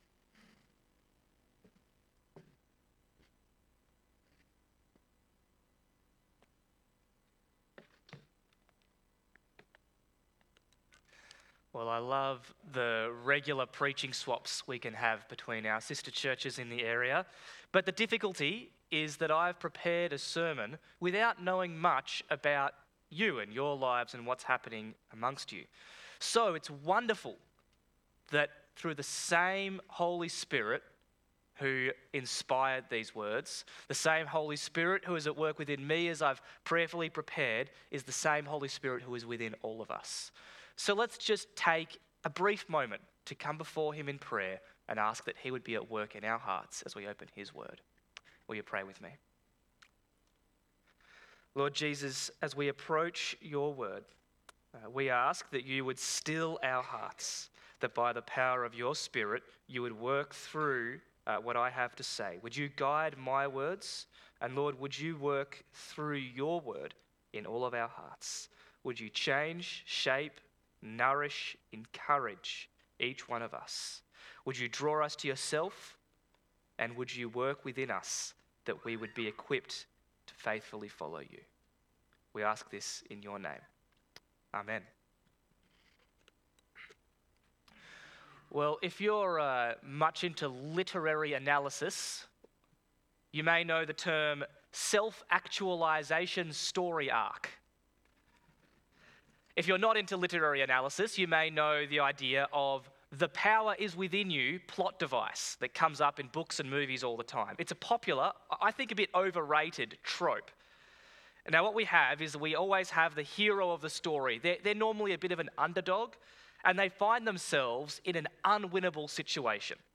Kingston Christian Reformed Church Sermons